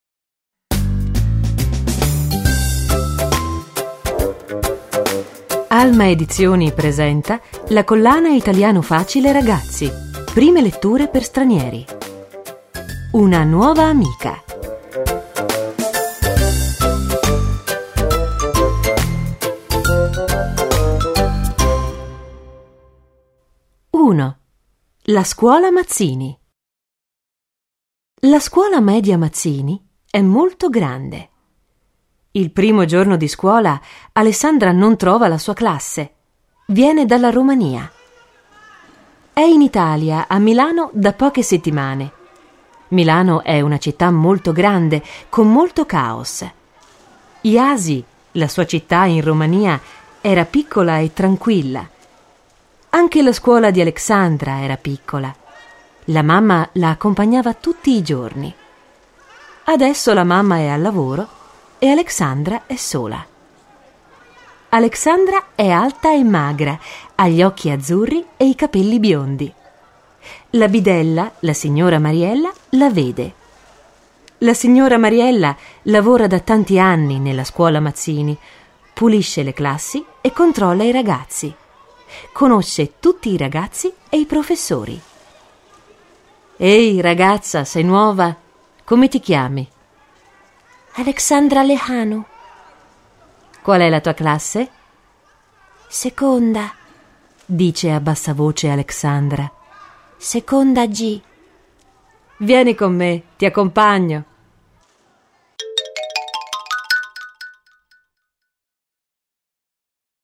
Il volume fa parte della collana Italiano facile per ragazzi, letture graduate con esercizi e versione audio del testo, con voci di attori professionisti ed effetti sonori realistici e coinvolgenti.